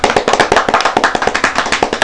A_clap.mp3